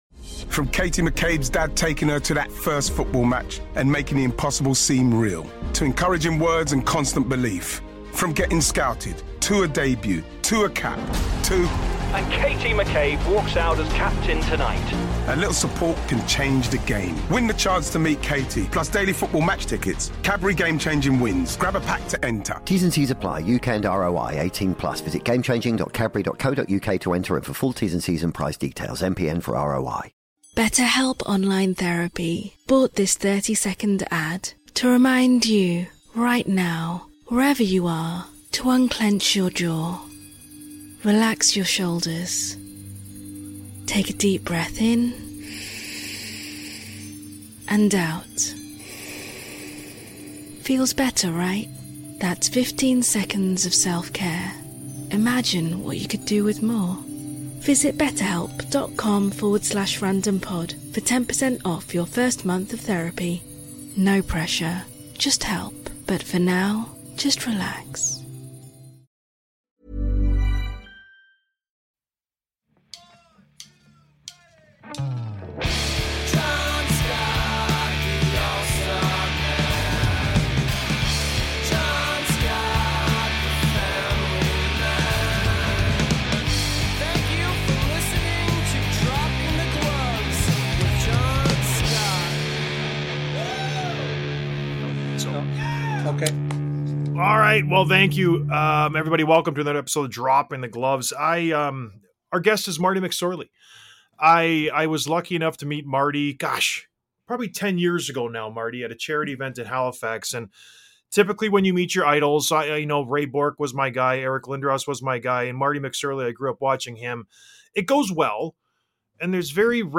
Interview with Marty McSorley